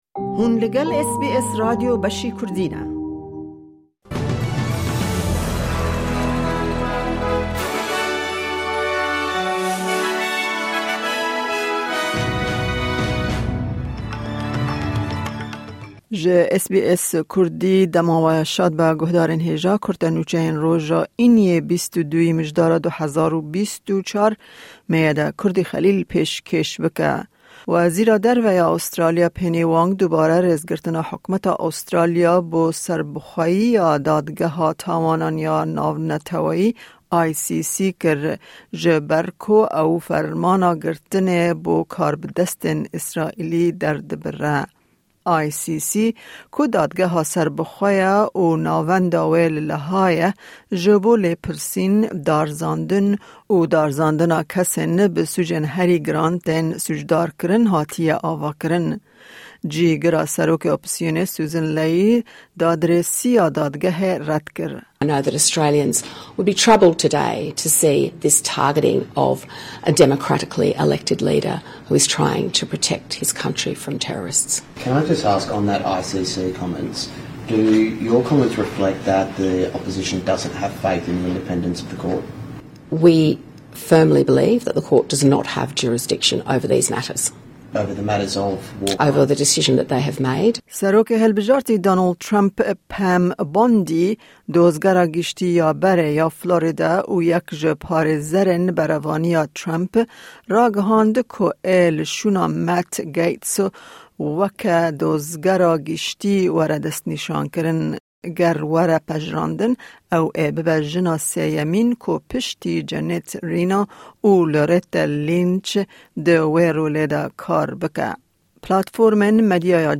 Kurte Nûçeyên roja Înî 2î Mijdara 2024